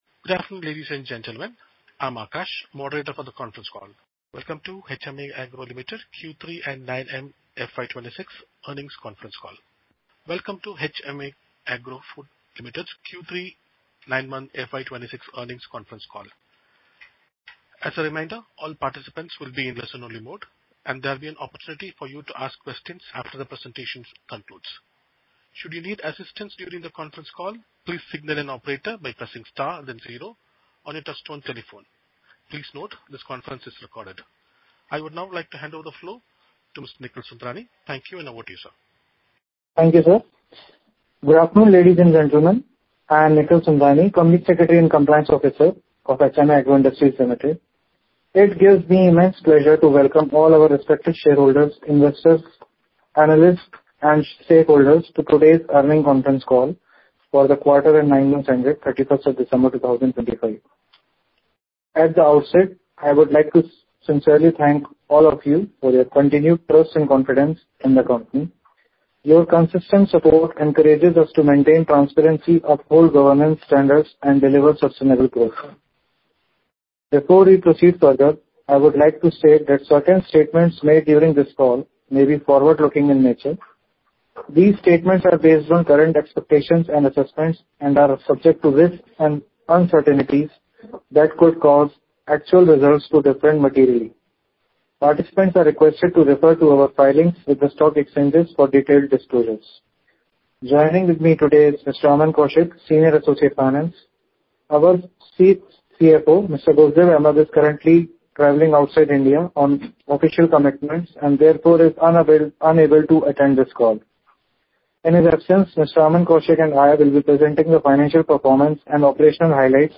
Audio Recording Of Investor Conference Call for QTR 3 9M Ended 31.12.2025